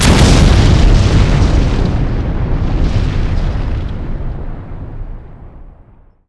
explode4.wav